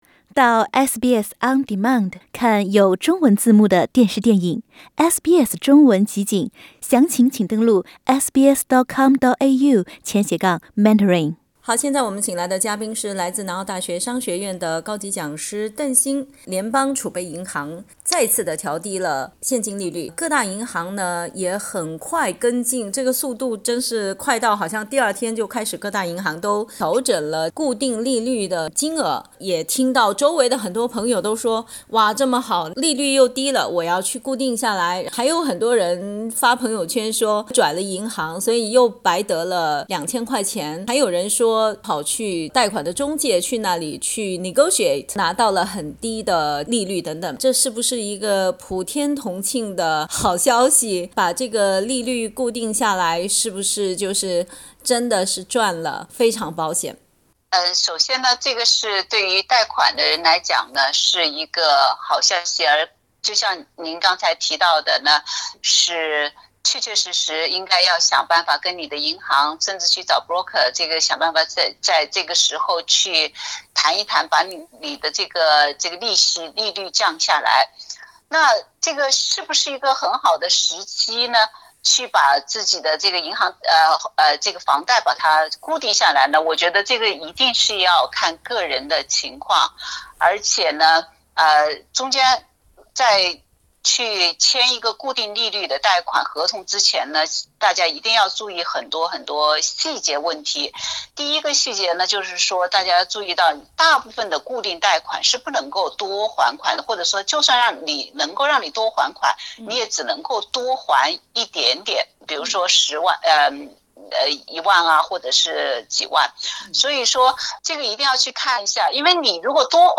（请听采访） 本节目为嘉宾观点，不代表本台立场。